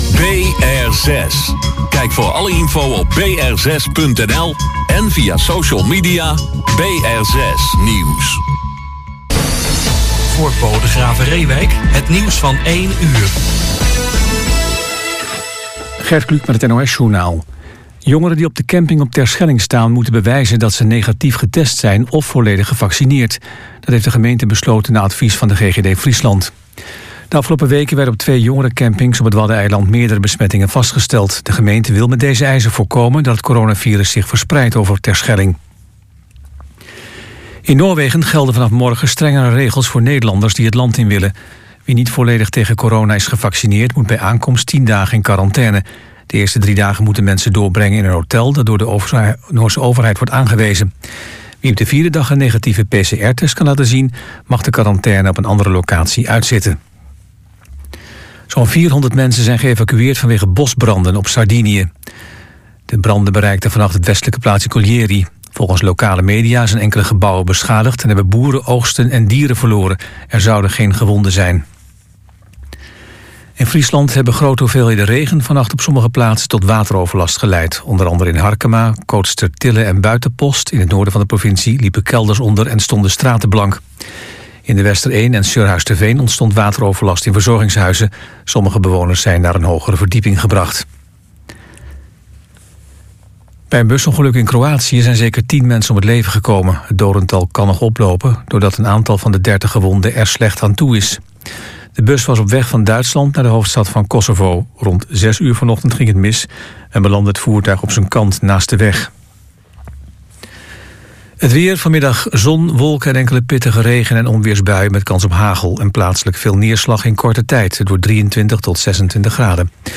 Als de muziek uit de luidspreker klinkt, volgt dan het nostalgische wegdromen op de zoetgevooisde zang of het onbedwingbare bewegen van linker- en rechtervoet op een heftige rock and roll.